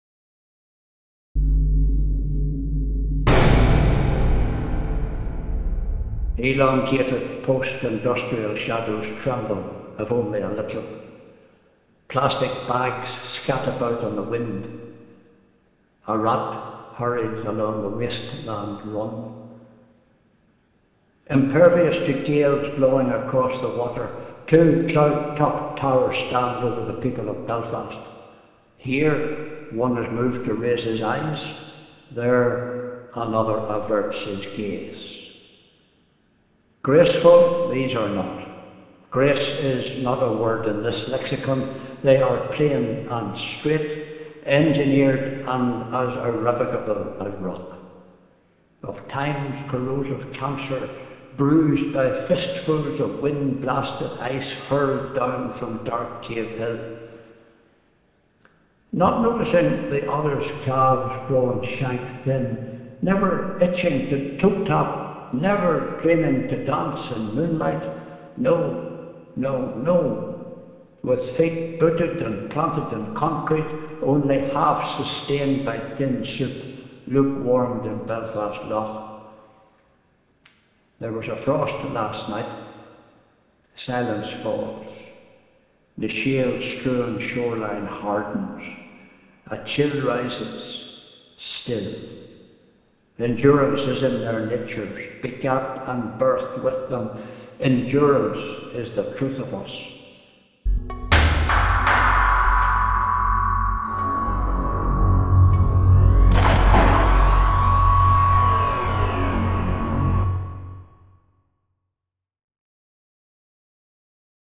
By the end of the two-week workshop, the participants had produced at least one complete work of sound, with some creating up to 4.